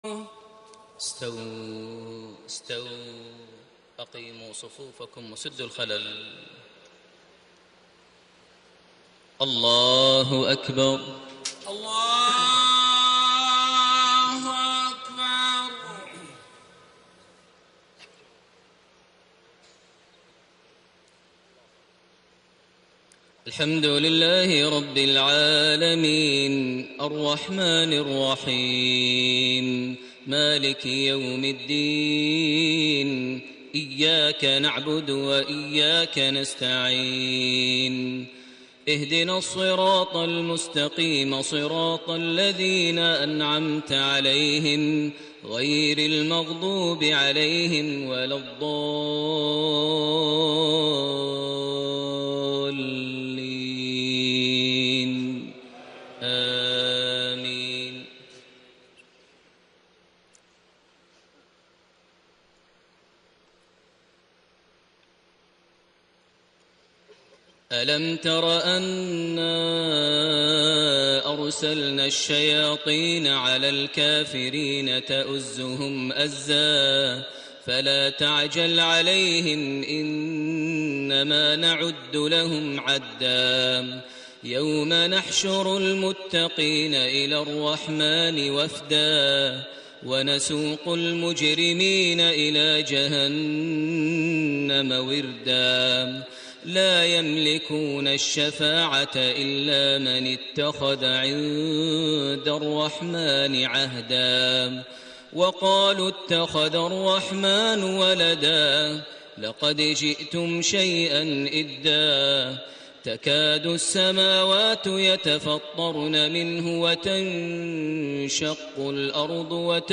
صلاة المغرب 6 شوال 1433هـ خواتيم سورة مريم 83-98 > 1433 هـ > الفروض - تلاوات ماهر المعيقلي